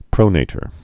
(prōnātər)